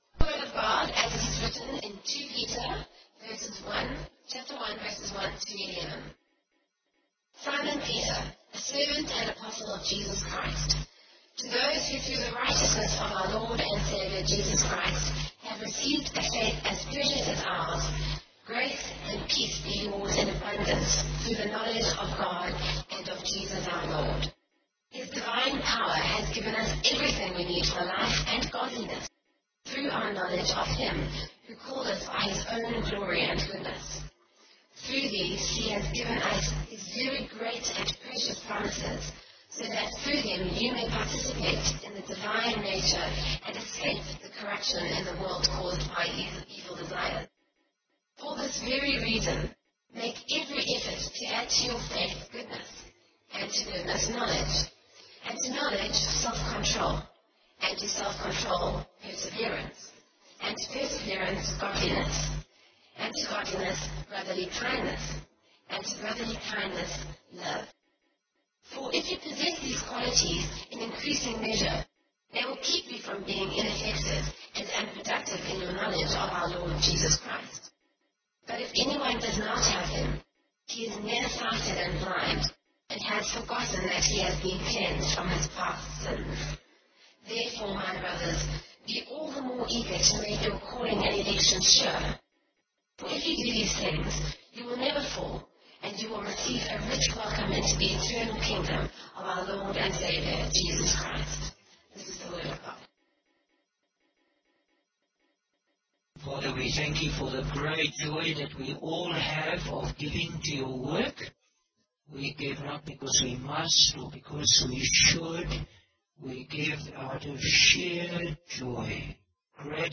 Bible Text: 2 Peter 1: 1-11 | Preacher: Bishop Warwick Cole-Edwards | Series: 2 Peter